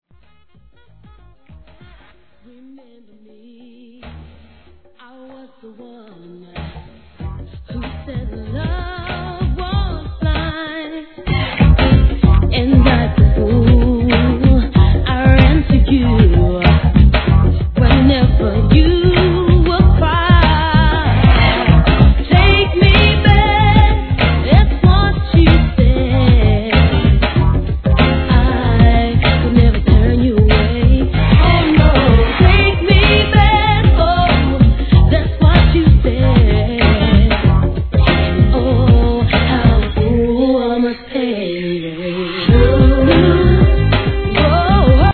HIP HOP/R&B
程よいテンポの跳ねたビートに絡むSAXが最高！